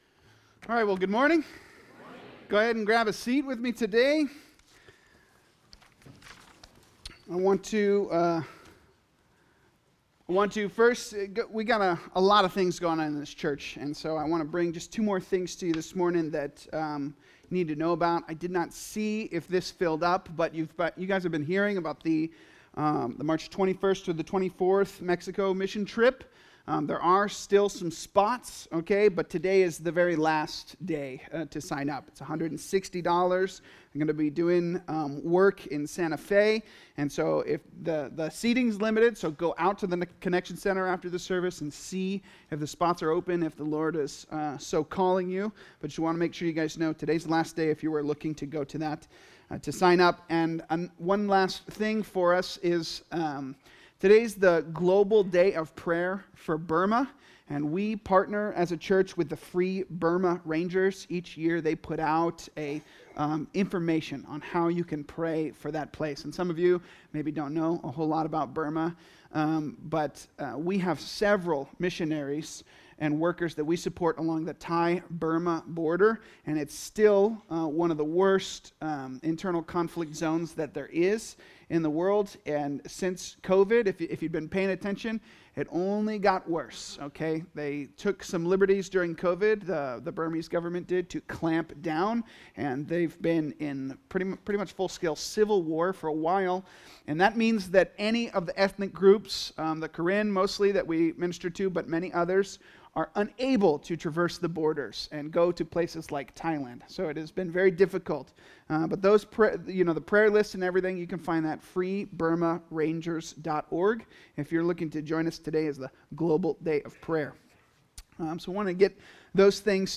Calvary Chapel Saint George - Sermon Archive
Related Services: Sunday Mornings